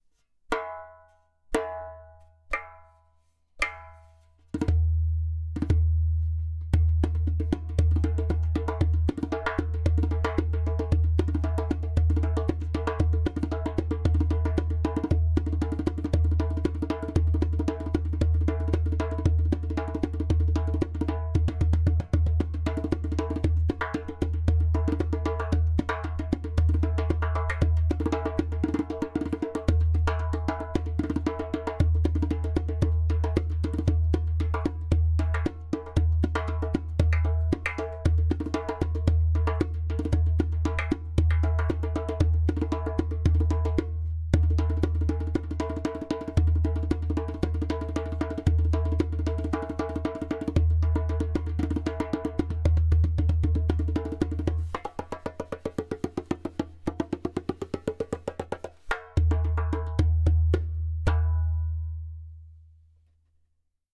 Premium Earth Bass 27cm Dohola with Goat skin
115bpm
• Strong and super easy to produce clay kik (click) sound
• Deep bass
• Beautiful harmonic overtones.